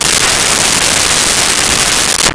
Car Start And Stall
Car Start and Stall.wav